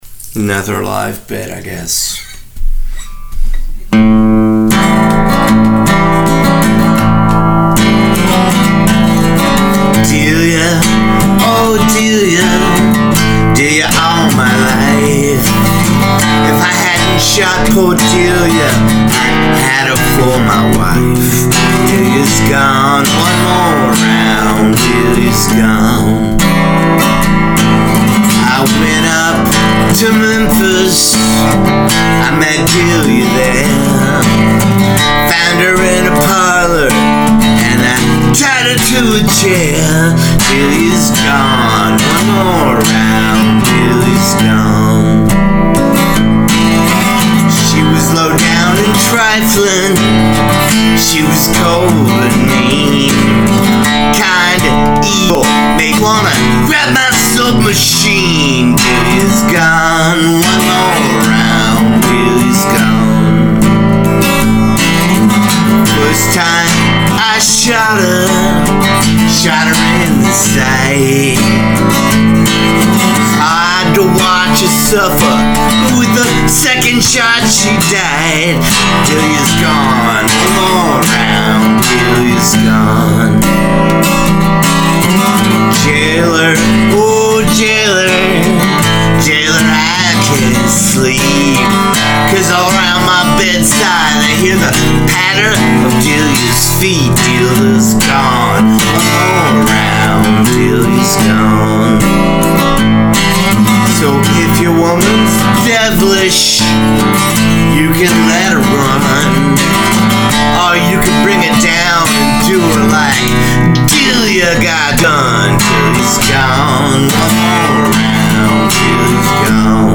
delias-gone-live.mp3